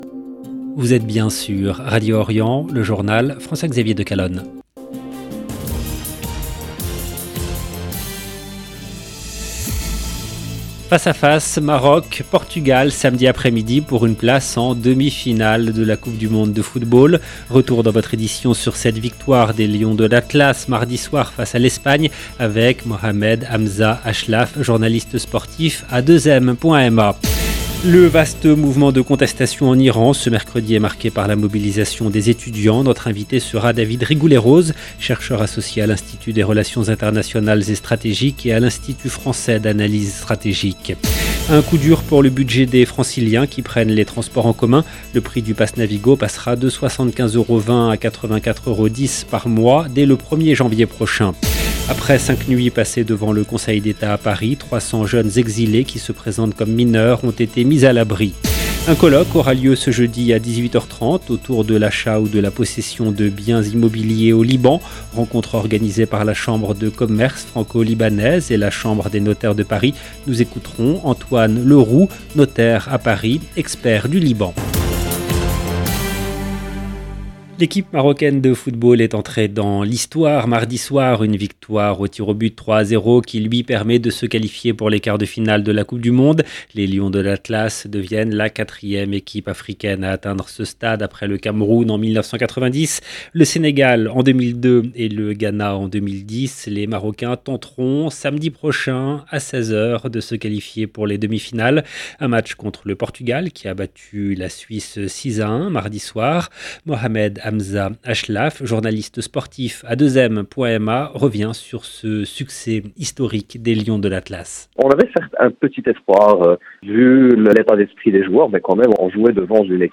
EDITION DU JOURNAL DU SOIR EN LANGUE FRANCAISE DU 7/12/2022